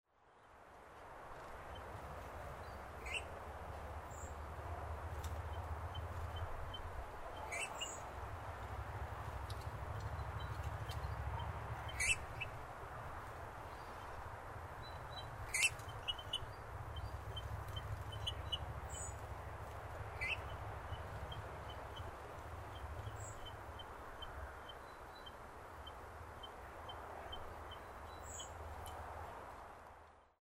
Bullfinch   Pyrrhula pyrrhula
Soft, quiet calls from two females and a male.